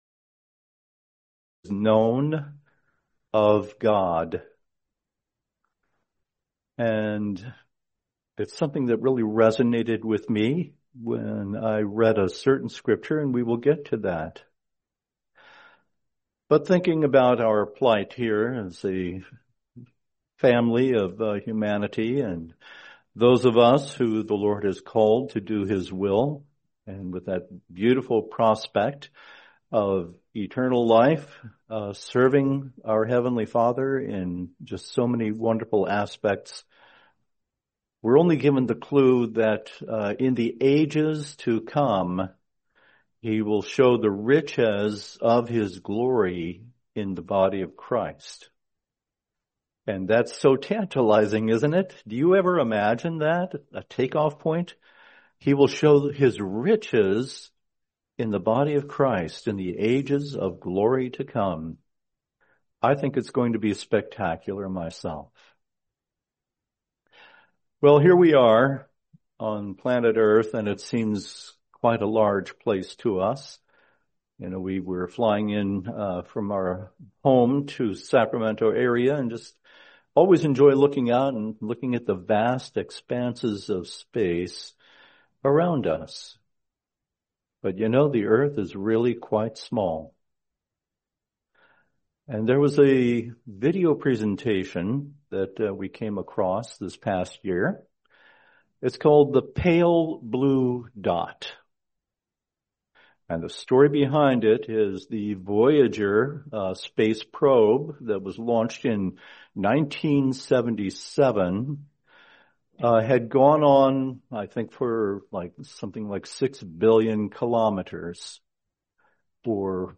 Series: 2026 Sacramento Convention